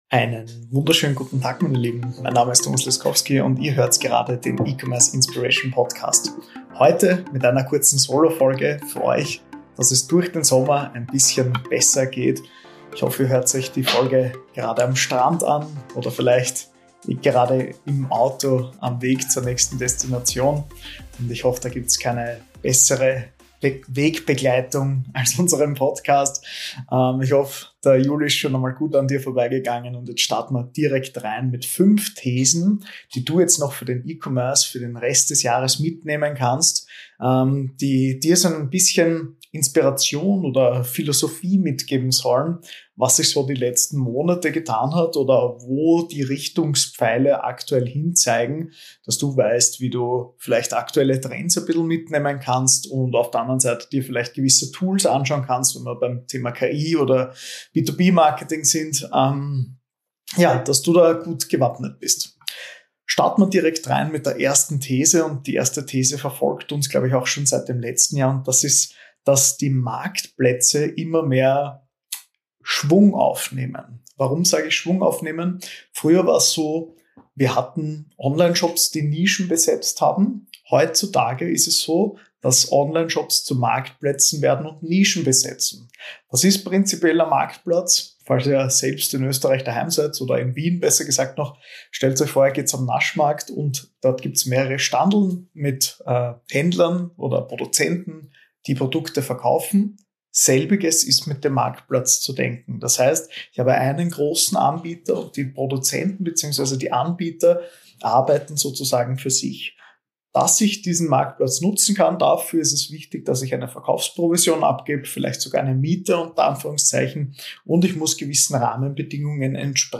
Beschreibung vor 8 Monaten Der österreichische E-Commerce verändert sich – und 2025 wird spannend: In dieser Solo-Folge teile ich meine fünf wichtigsten Thesen für Händler:innen, Agenturen und alle, die im E-Commerce aktiv sind: Marktplatz-Modelle statt Vollsortiment – warum Kuratoren erfolgreicher sein können als reine Eigenhändler. KI als Effizienzbooster – wie du von Chatbots bis Content-Tools profitierst.